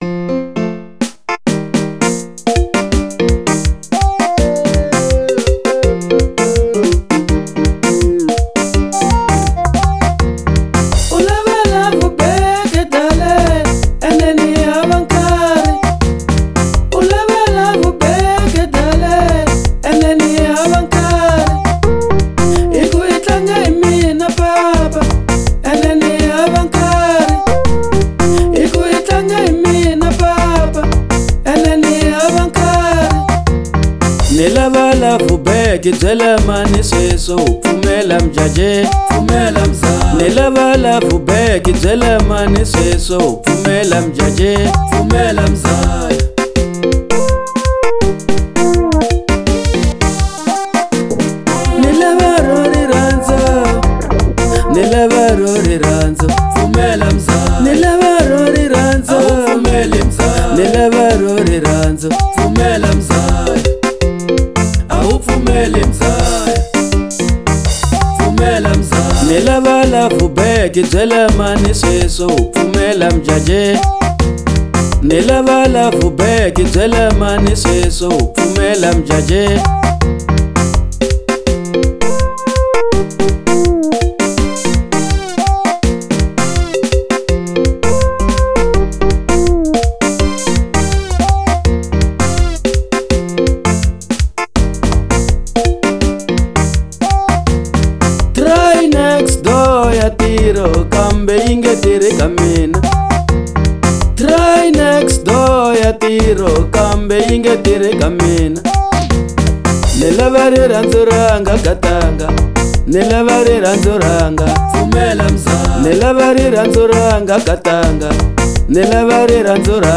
04:27 Genre : Xitsonga Size